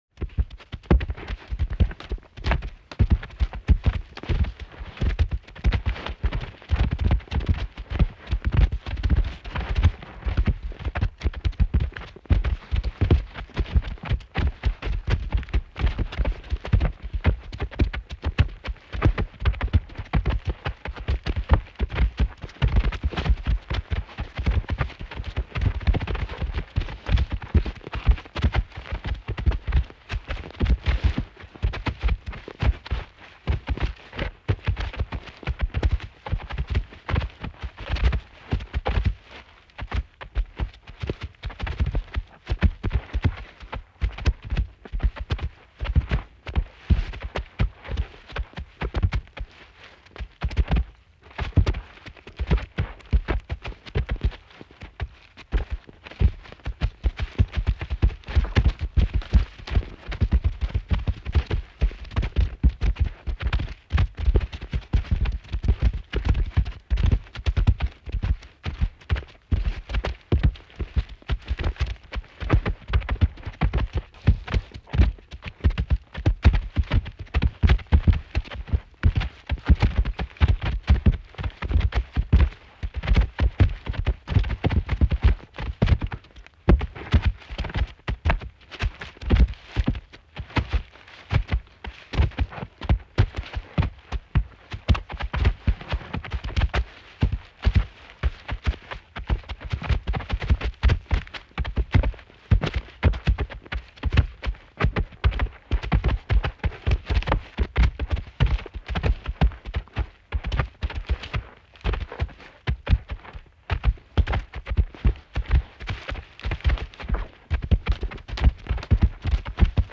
دانلود آهنگ فیل 6 از افکت صوتی انسان و موجودات زنده
جلوه های صوتی
دانلود صدای فیل 6 از ساعد نیوز با لینک مستقیم و کیفیت بالا